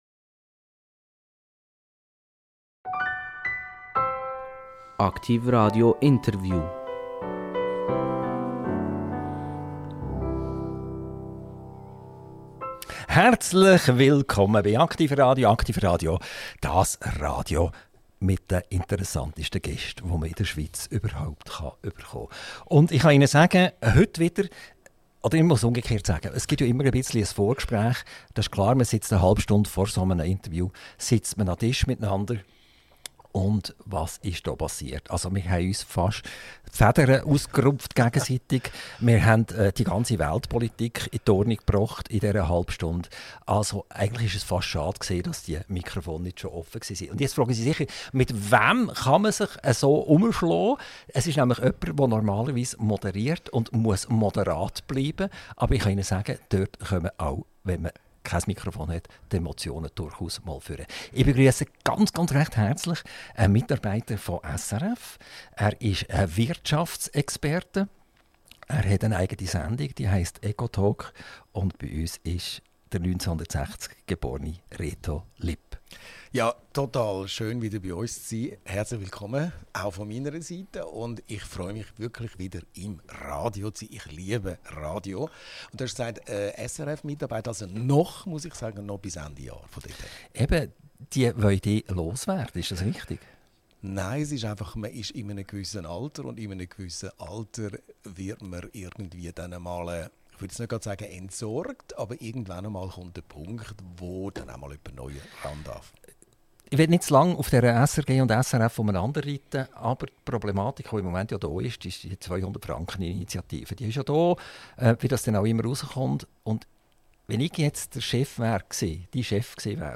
INTERVIEW - Reto Lipp - 29.10.2025 ~ AKTIV RADIO Podcast